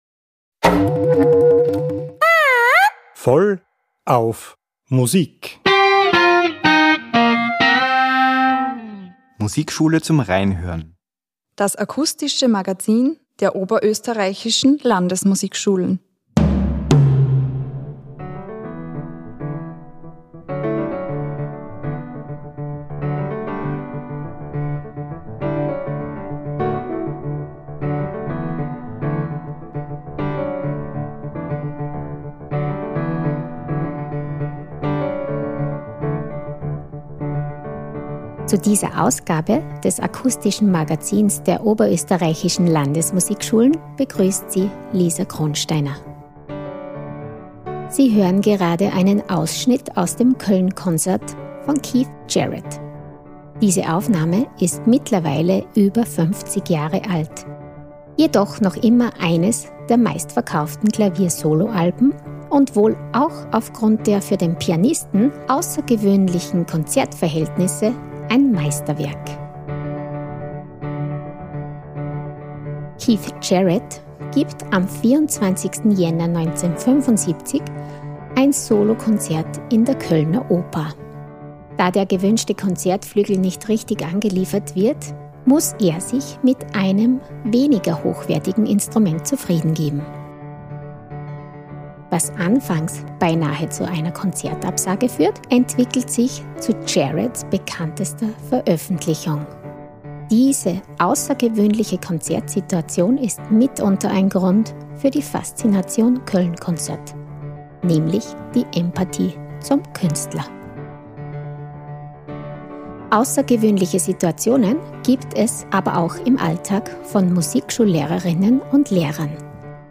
Ein sehr persönliches Gespräch über die Arbeit in der Musikschule und grenzenloses Lehren sowie Lernen durch Inklusion.